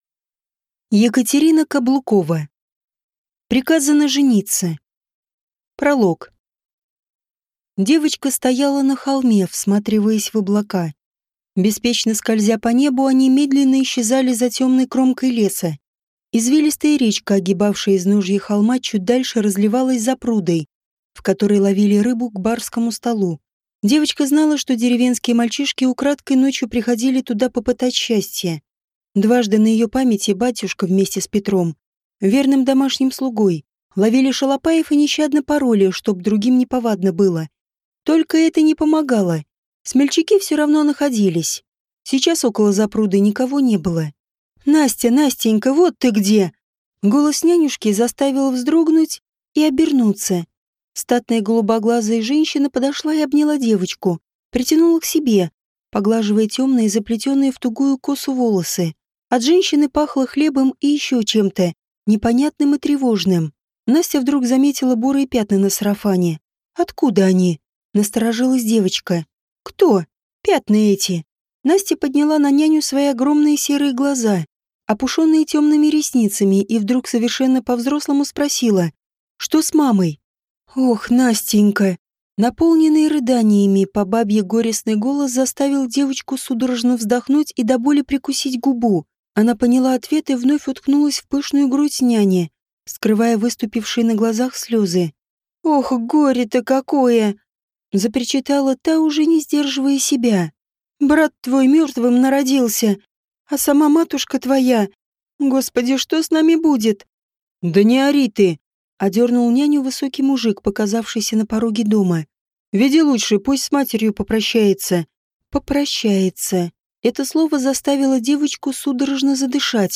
Аудиокнига Приказано жениться | Библиотека аудиокниг